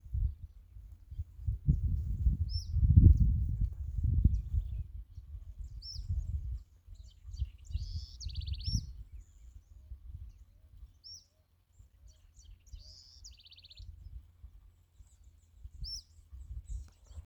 Guaracava-modesta (Sublegatus modestus)
Nome em Inglês: Southern Scrub Flycatcher
Condição: Selvagem
Certeza: Fotografado, Gravado Vocal
Suiriri-pico-corto_1.mp3